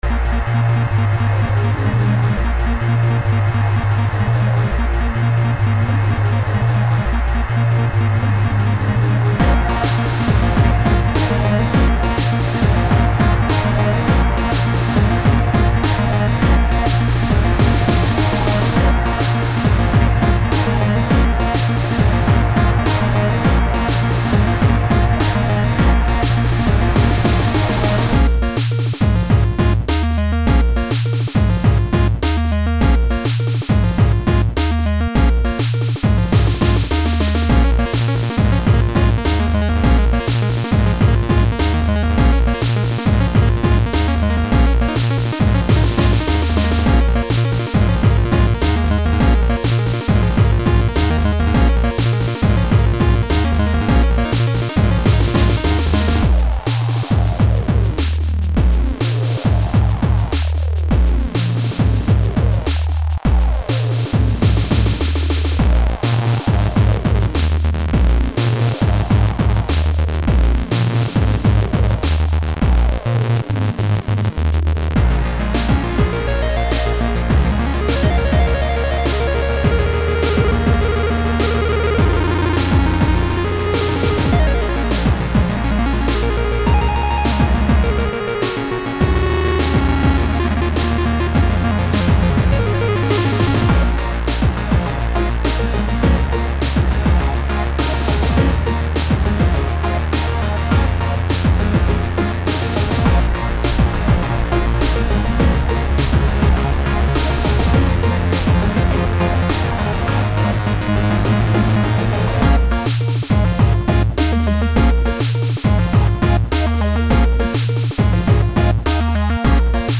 What you have is the unmastered, uneditted soundtrack.